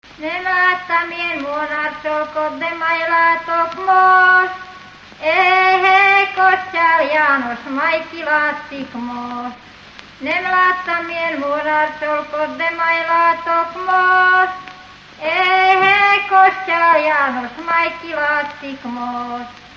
Felföld - Nyitra vm. - Menyhe
ének
Műfaj: Lakodalmas
Stílus: 7. Régies kisambitusú dallamok